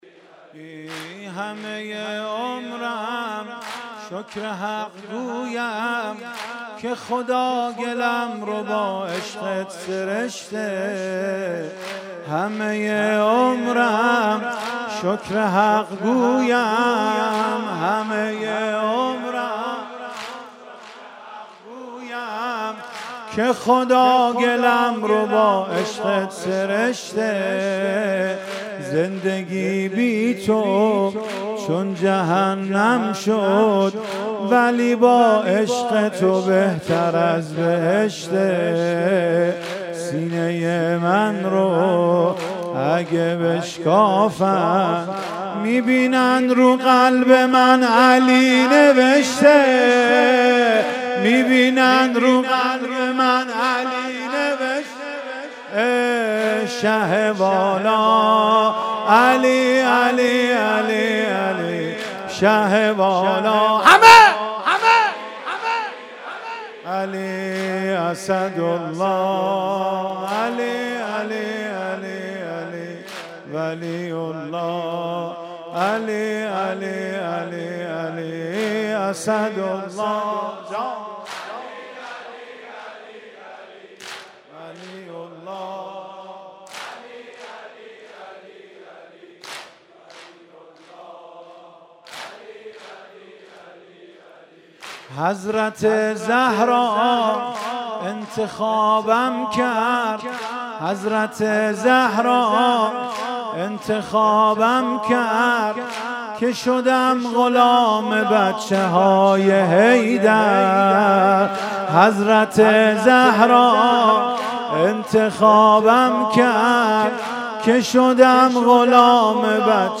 16 خرداد 97 - هیئت مصباح الهدی - واحد - همه ی عمر شکر حق گویم
ماه مبارک رمضان